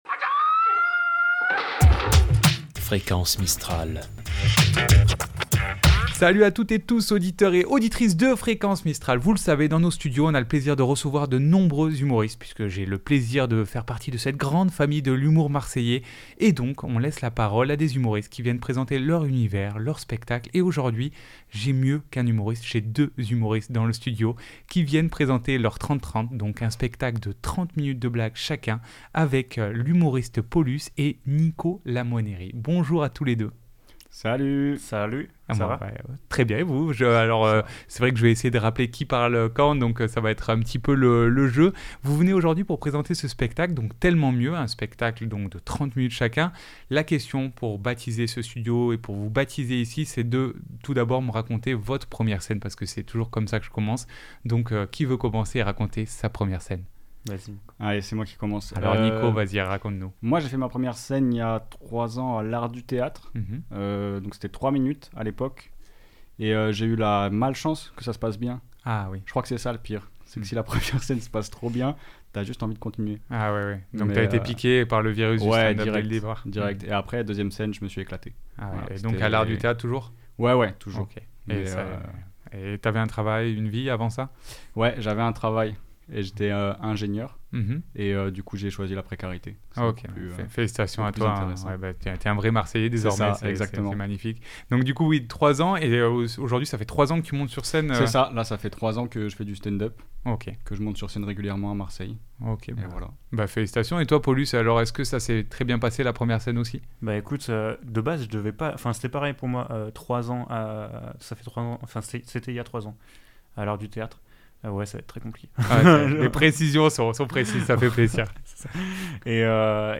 ITW Tellement mieux